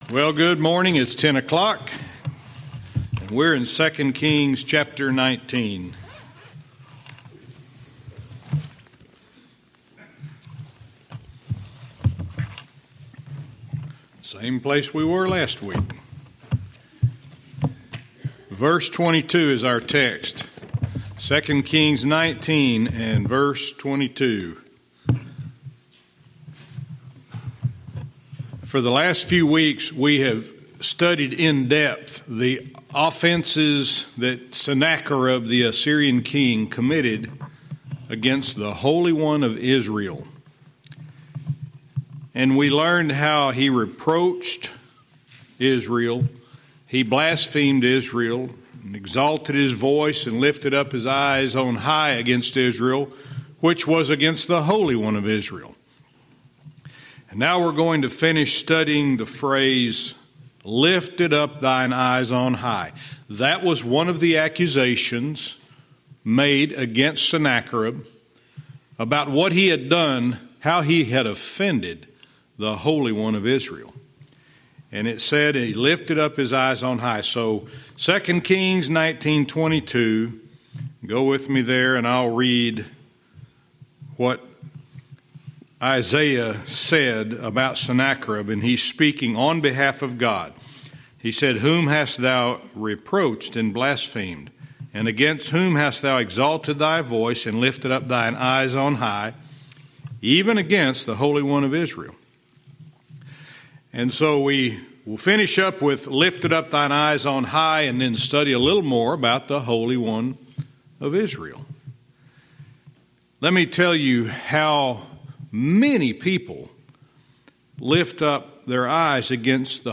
Verse by verse teaching - 2 Kings 19:22(cont)